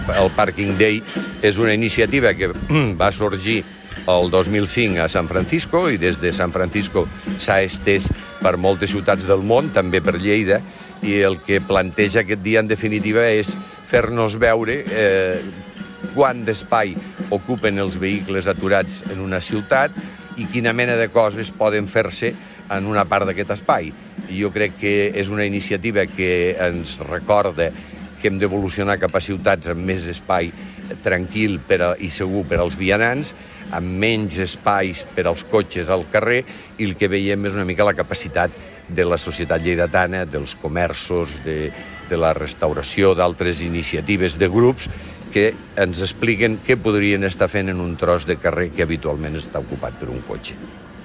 Tall de veu M. Pueyo
tall-de-veu-m-pueyo